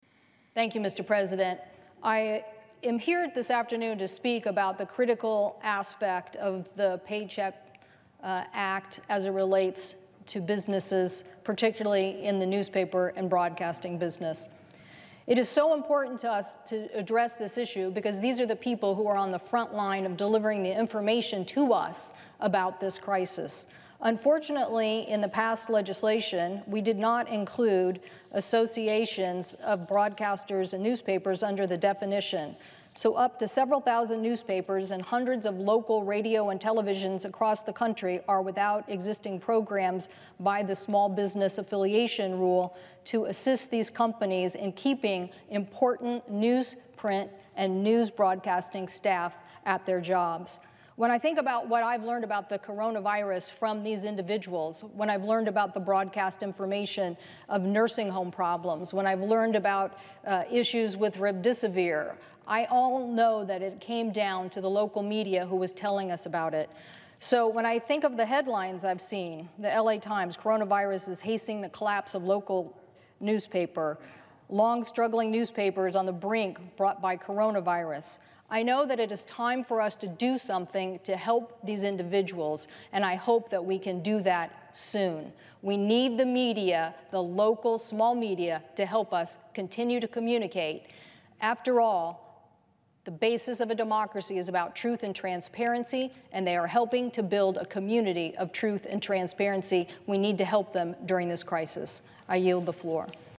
local-media-senate-floor-audio&download=1